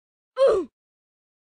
без слов
из игры